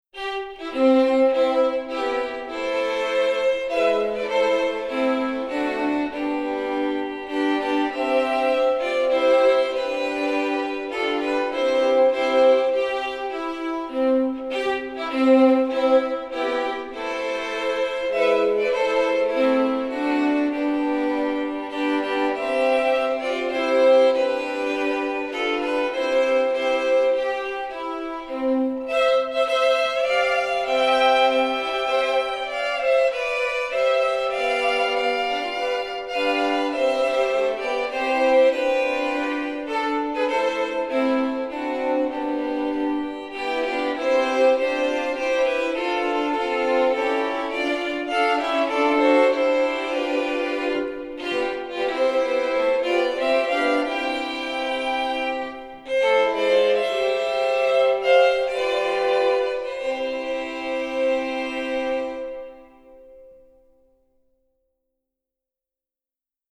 Voicing: 4 Violins